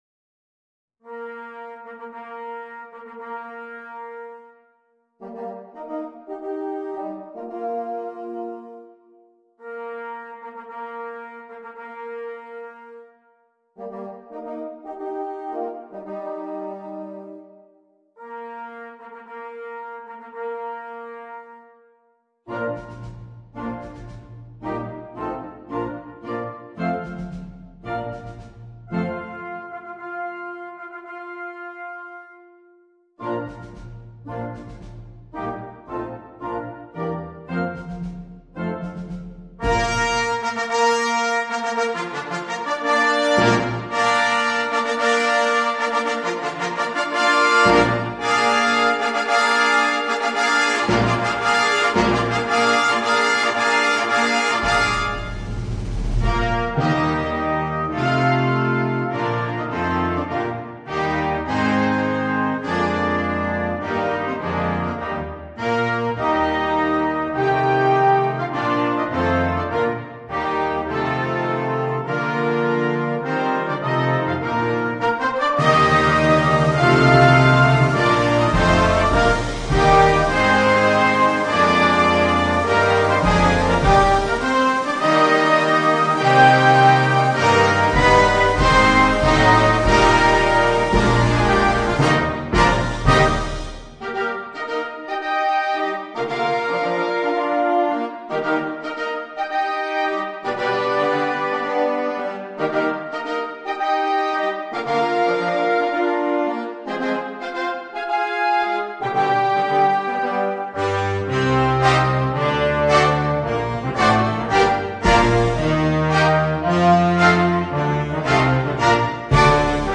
Gran marcia trionfale